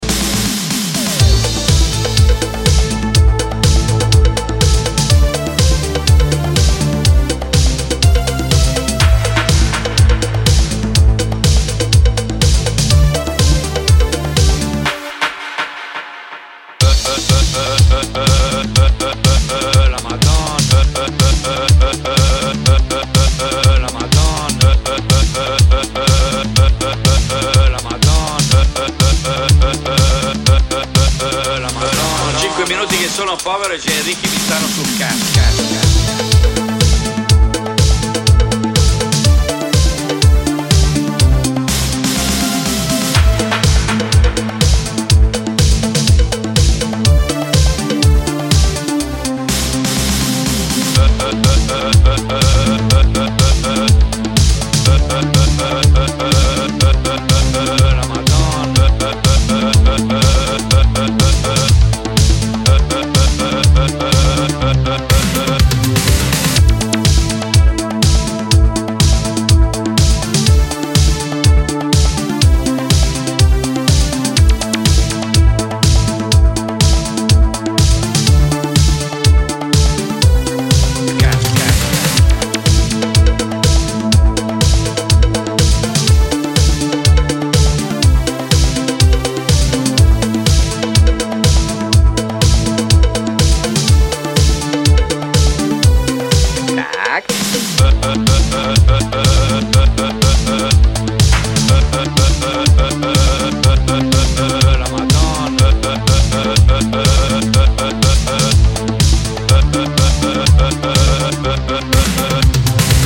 proto-house ruggedness and glossy Italo disco melodies